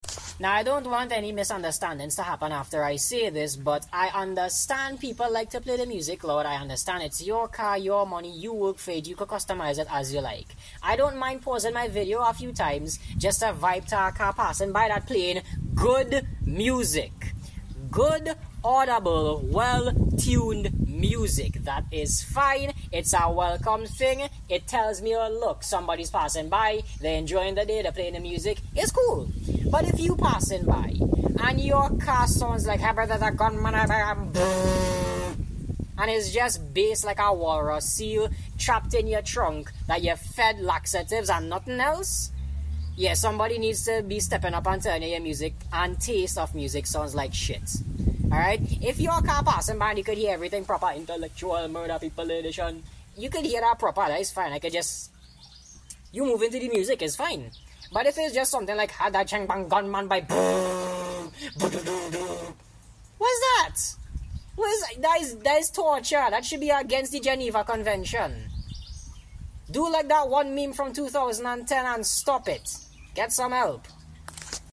yes. some songs have heavy bass. stop trying to put bass on songs that inherently don't have any